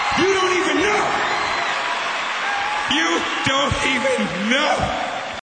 Dane Cook screams: "You don't even know!"